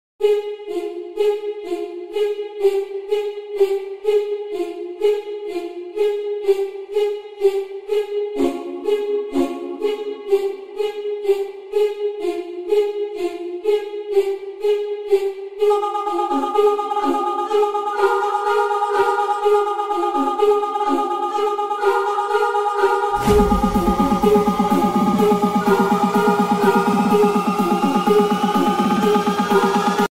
Kategoria Efekty Dźwiękowe